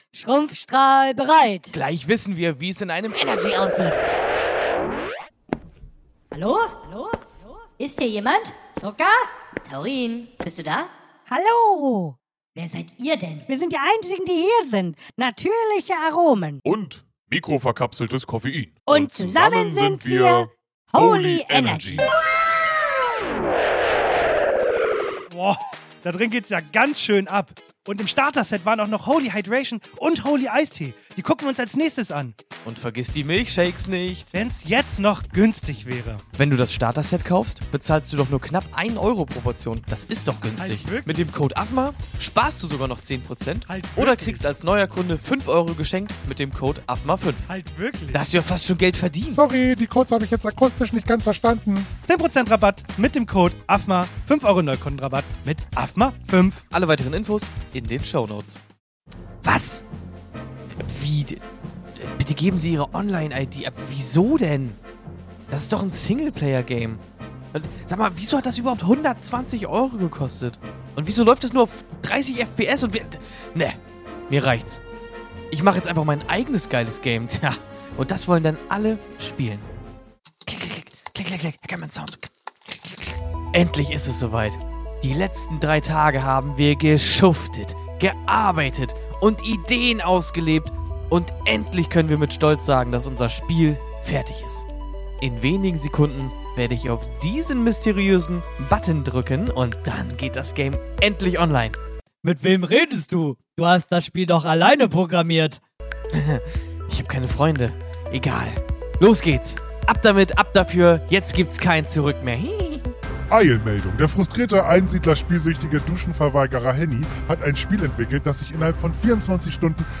#204 - Was passiert, wenn man zwei sympathische, gutaussehende Jungs mit Mikros auf eine Messe schickt?
Wir waren für euch auf der Caggtus in Leipzig, haben natürlich wieder mit den Gästen und (anderen) Creator:innen gesprochen und gefragt was so abgeht.